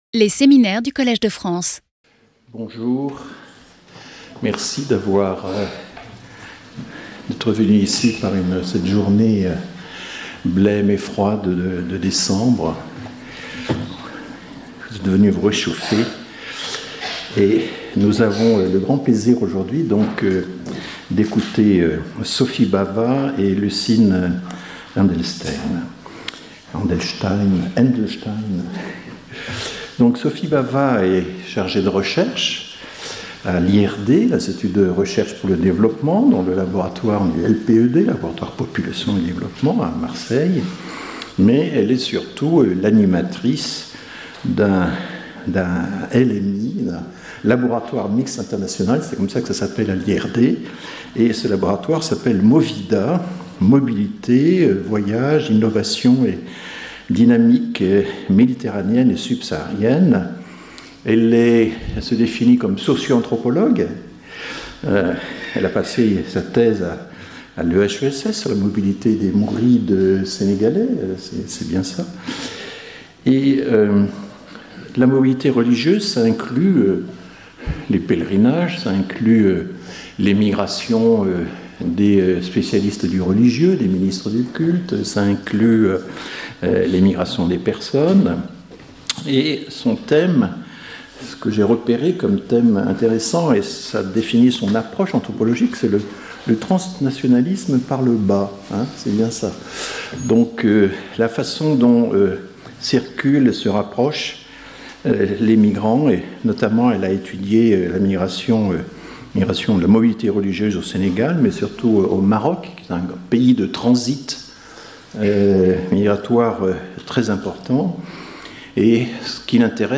Sauter le player vidéo Youtube Écouter l'audio Télécharger l'audio Lecture audio Exceptionnellement, le séminaire aura lieu un lundi.